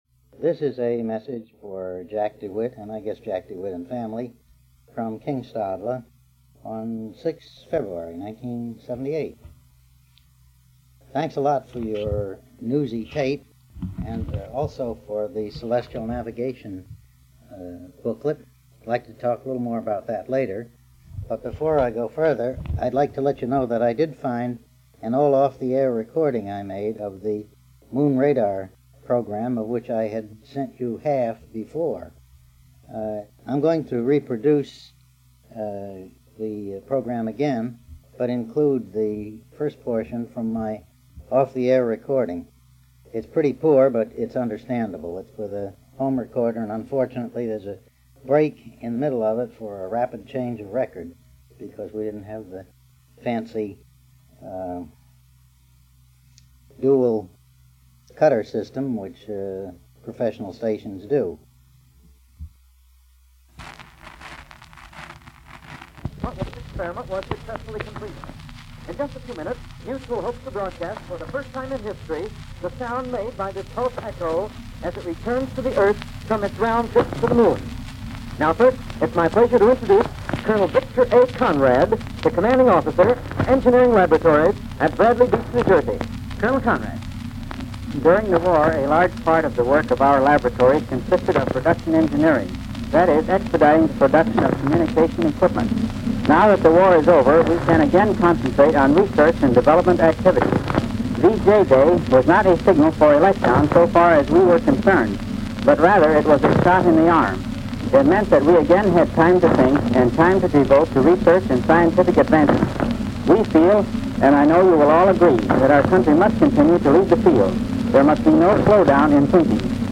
Radio Broadcast about Project Diana, Mutual Broadcasting System (MBS)